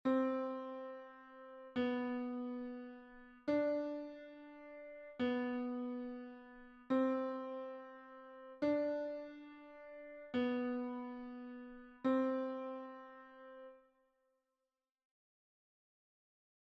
note recognition exercise 4
4_note_recognition_clave_sol_B_grave_8_notas.mp3